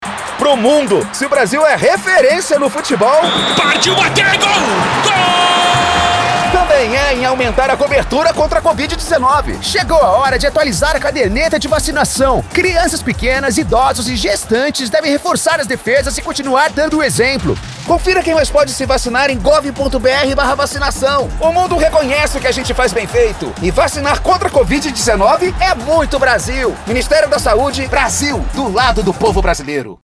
Áudio - Spot - 30s - Campanha Vacinação Covid-19 — Ministério da Saúde